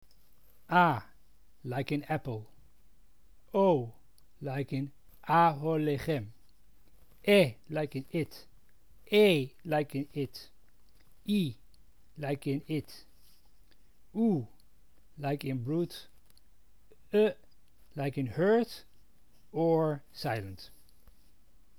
This page describes the rules of pronunciation, as used by the Amsterdam Portuguese community.